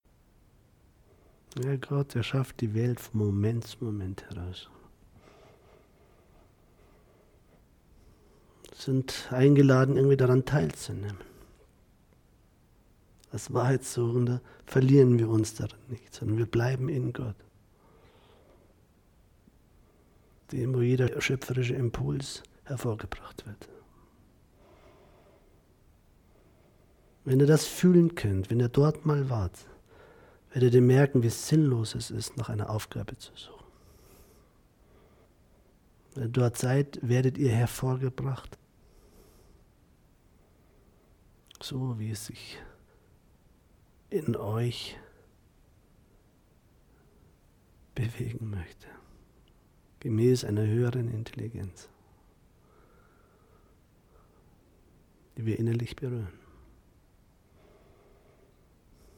Meditation Live-Aufnahme
Mir gefällt diese geführte Meditation außerordentlich gut.